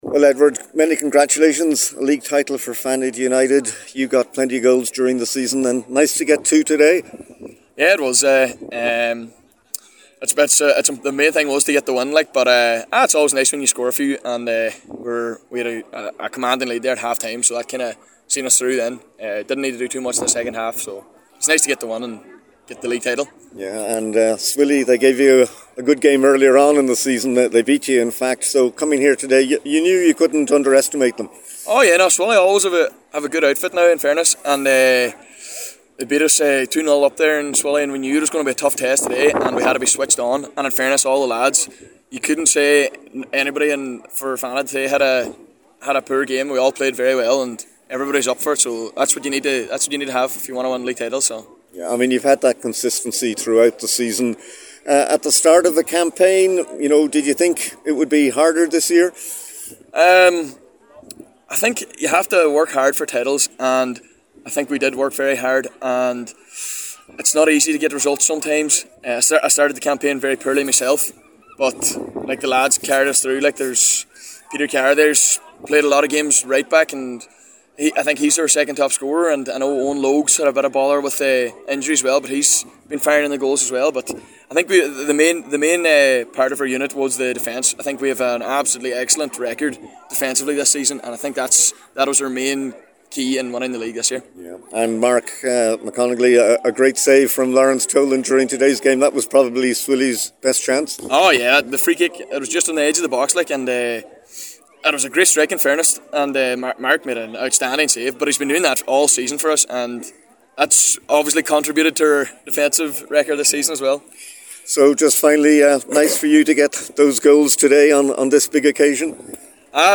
at full-time to share his thoughts on a successful season.